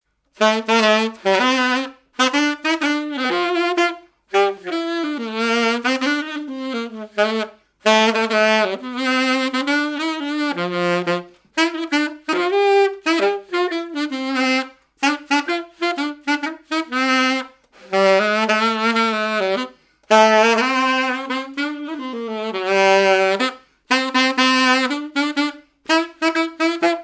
danse : marche
Genre strophique
activités et répertoire d'un musicien de noces et de bals
Pièce musicale inédite